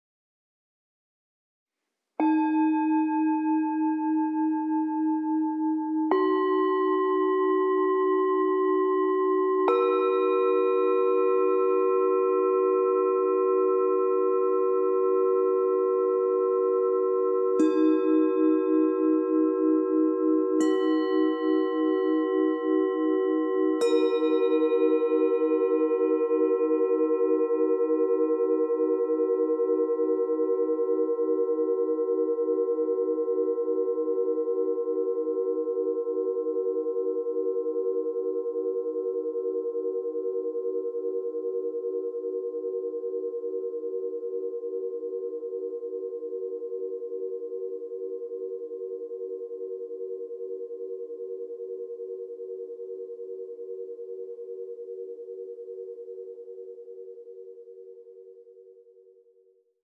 Meinl Sonic Energy 3-piece Yoga Nidra Singing Bowl Set (SB-Y1)
The Meinl Sonic Energy 3-piece Yoga Nidra Singing Bowl Set is handcrafted in India with quality-sounding instruments made from special bronze alloy.…